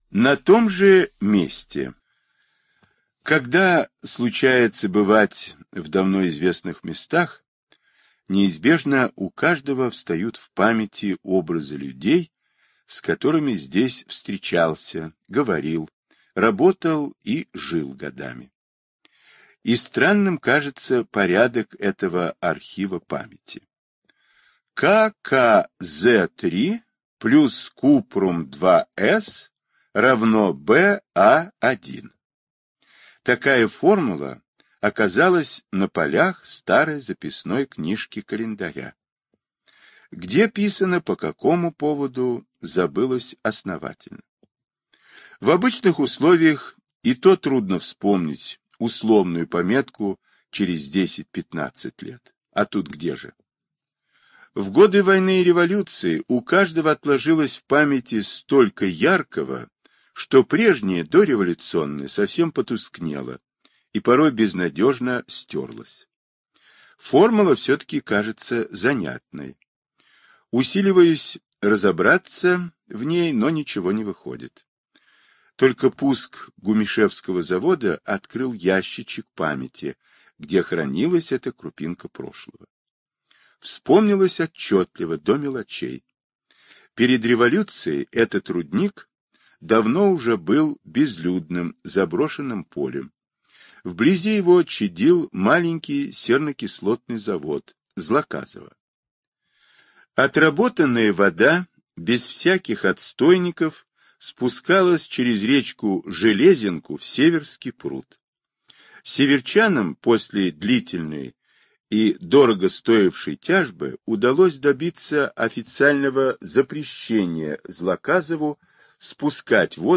На том же месте - аудиосказка Павла Бажова - слушать онлайн